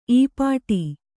♪ īpāṭi